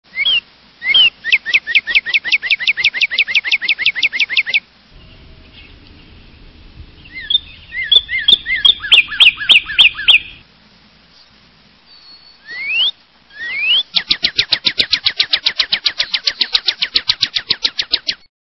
Northern Cardinal
Northern_Cardinal.mp3